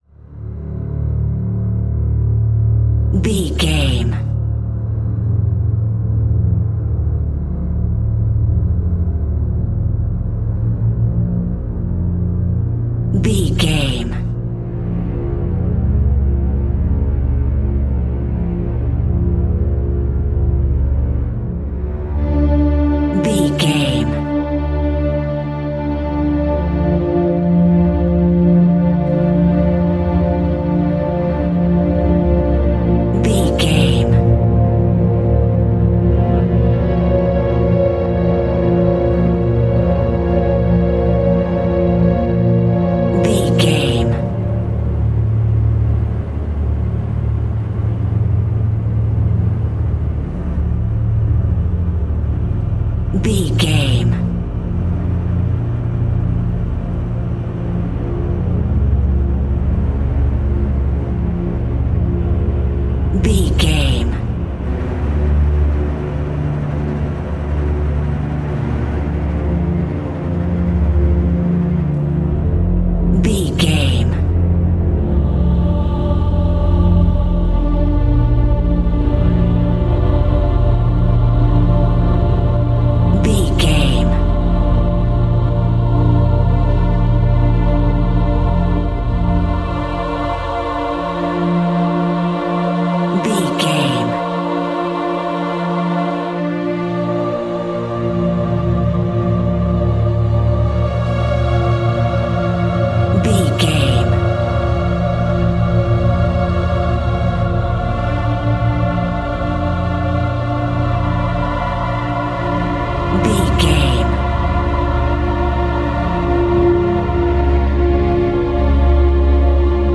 Aeolian/Minor
D
scary
tension
ominous
dark
suspense
haunting
eerie
strings
synth
ambience
pads